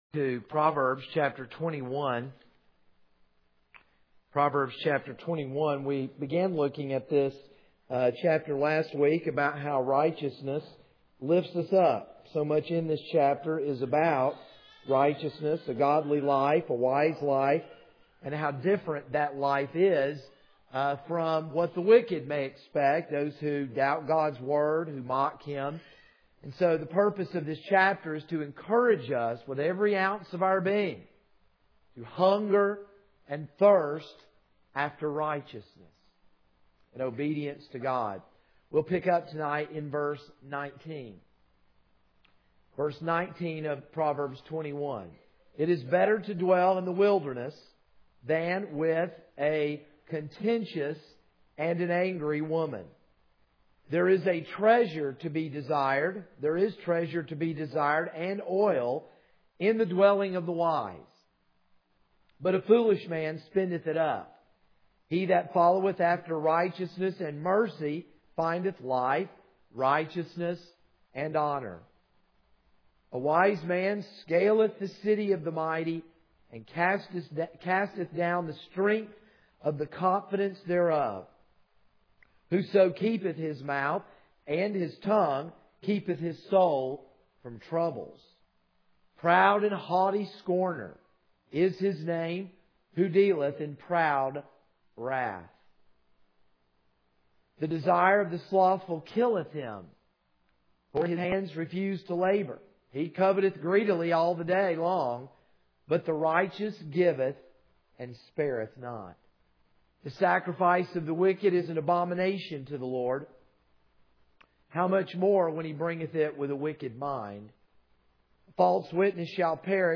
This is a sermon on Proverbs 21:19-31.